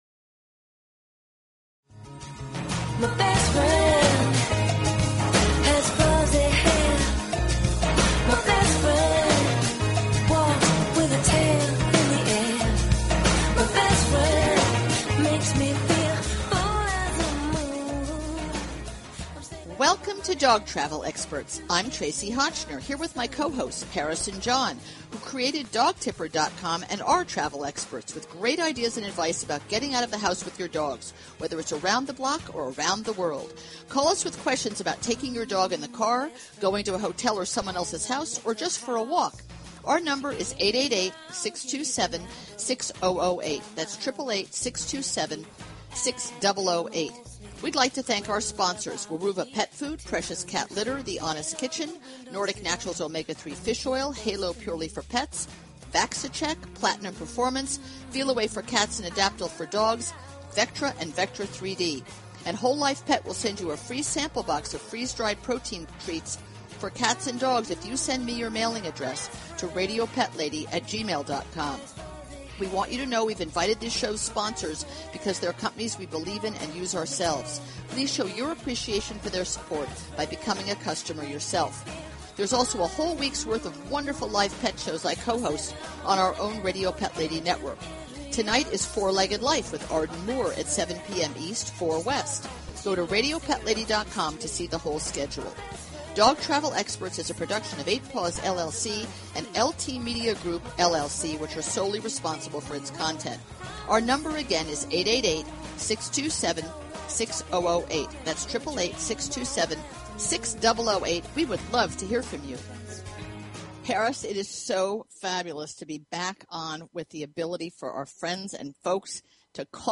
Talk Show Episode, Audio Podcast, Dog_Travel_Experts and Courtesy of BBS Radio on , show guests , about , categorized as